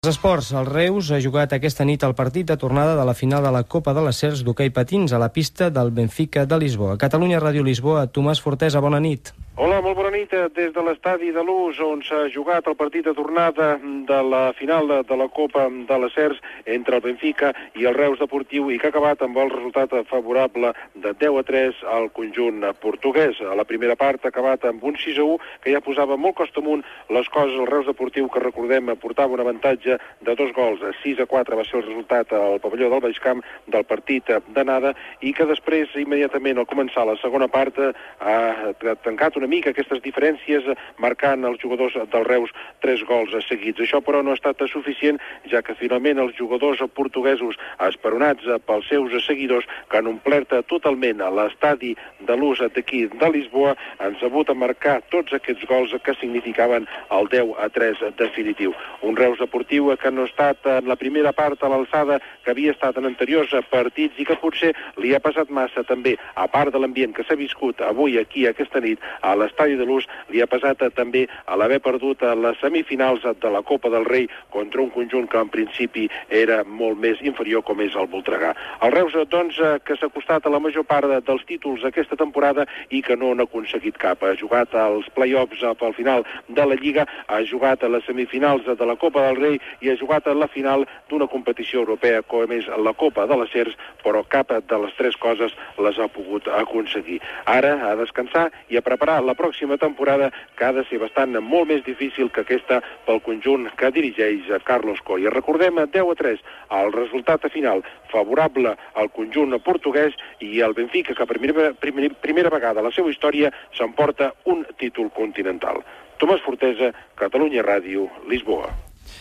Crònica des de Lisboa, Portugal, de la final masculina de la Copa CERS d'hoquei parins, que el Reus perd contra el Benfica
Esportiu